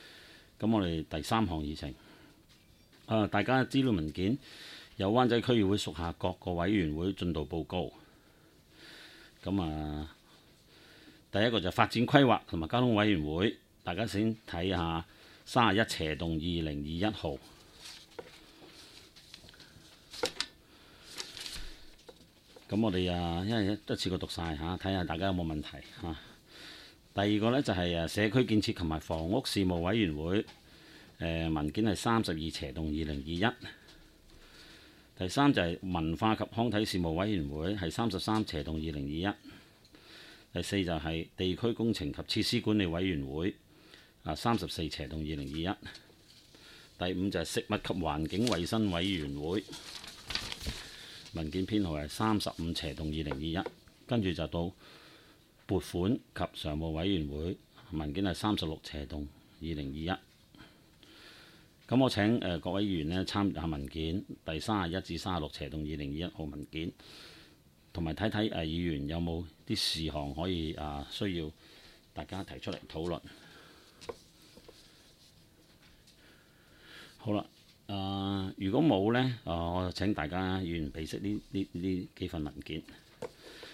区议会大会的录音记录
湾仔区议会第十四次会议
湾仔民政事务处区议会会议室